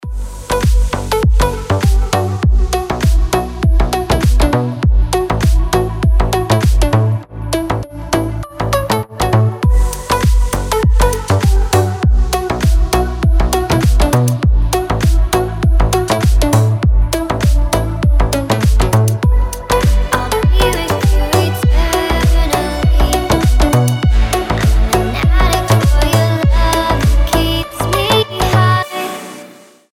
• Качество: 320, Stereo
deep house
мелодичные
красивый женский голос